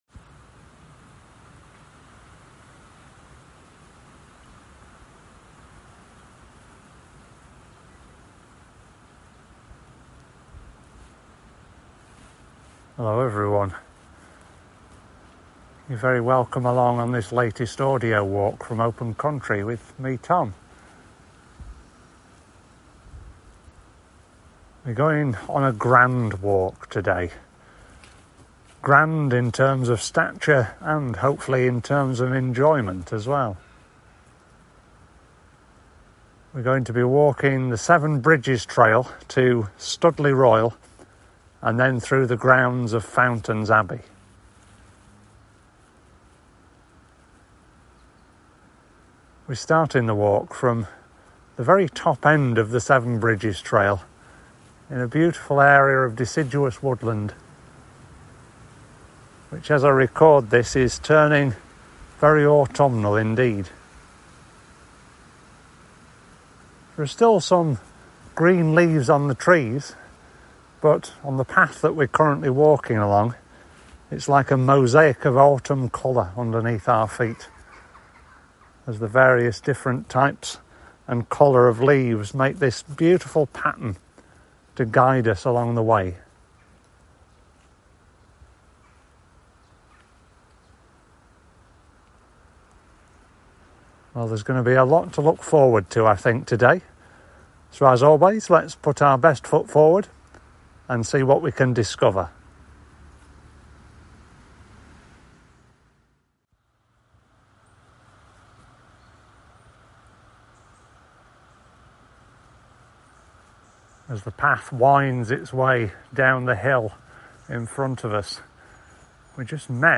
This audio trail accompanies: Fountains Abbey and Studley Royal